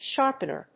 音節shárp・en・er 発音記号・読み方
音節sharp･en･er発音記号・読み方ʃɑ́ːrp(ə)nər